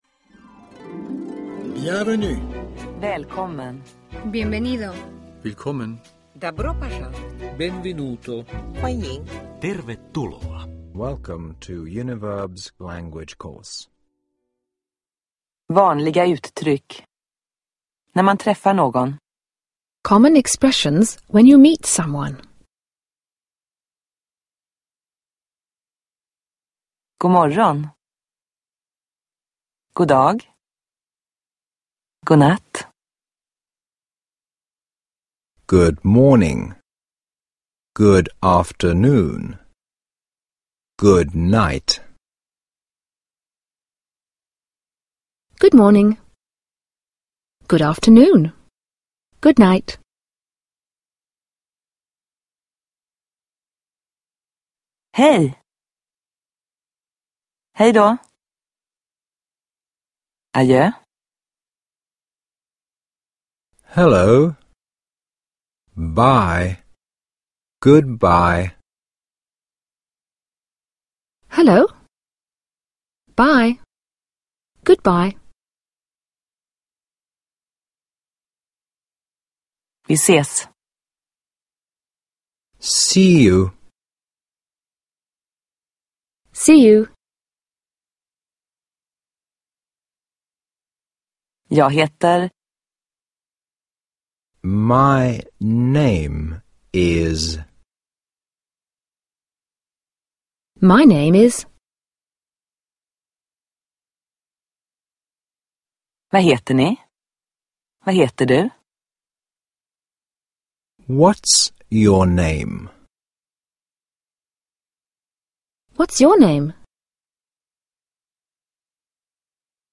Engelsk språkkurs – Ljudbok
Lyssna – Du hör en fras på svenska, därefter två gånger på engelska.